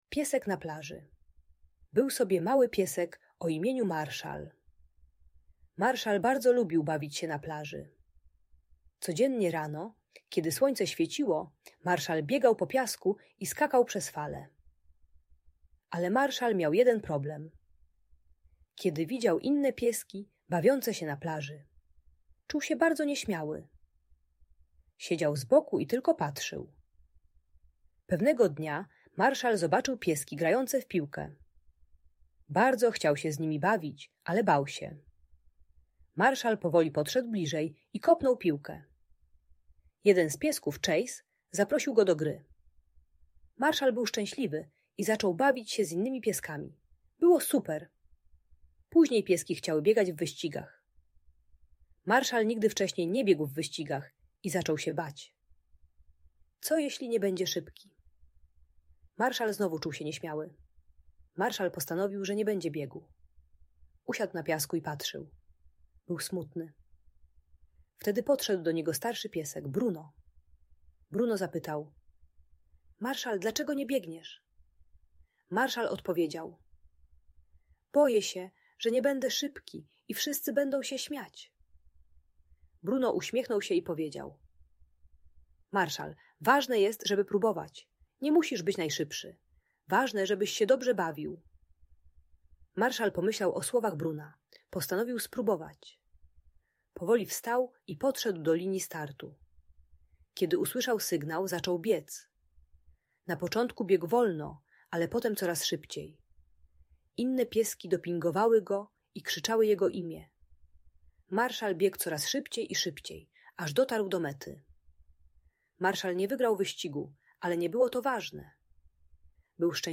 Story o piesku na plaży - Audiobajka